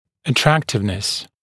[ə’træktɪvnəs][э’трэктивнэс]привлекательность